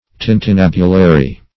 Tintinnabulary \Tin`tin*nab"u*la*ry\